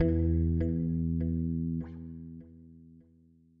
这些样本是使用各种硬件和软件合成器以及外部第三方效果创建的。